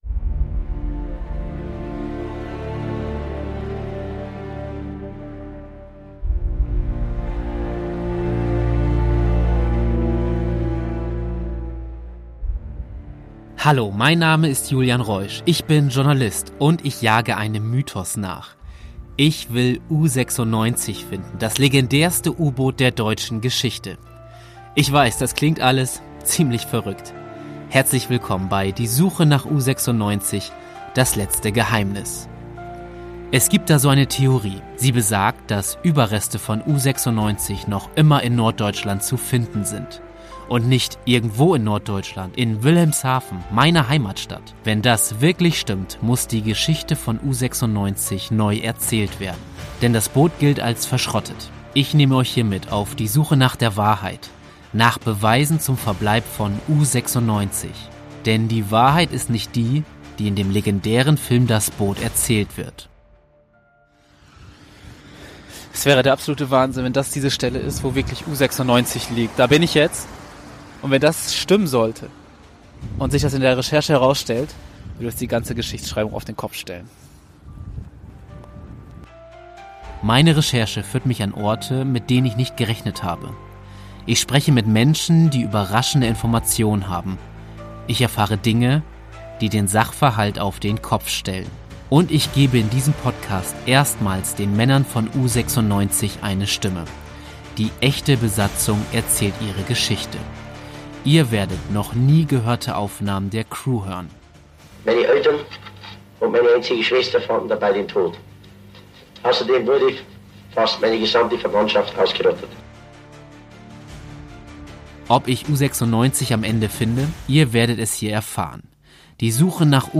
Besatzung erzählt ihre Geschichte.